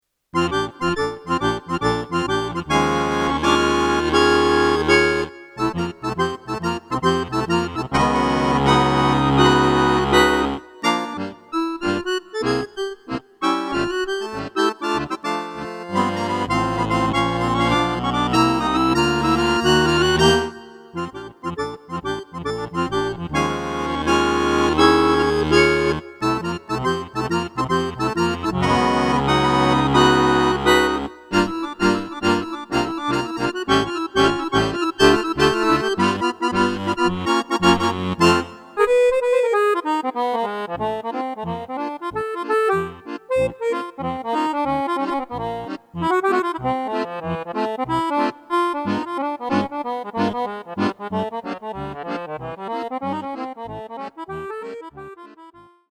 Keine Verkleidung diesmal, keine Bearbeitung, nix, so wie's steht (fast).